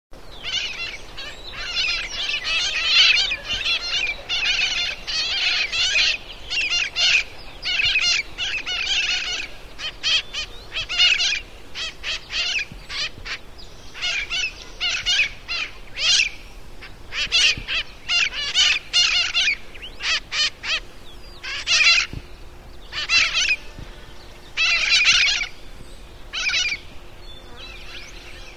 Mitred Parakeet
They are even used as "watch birds", given their loud, piercing alarm call.
Bird Sound
"weee weee", "cheeah cheeah"
MitredParakeet.mp3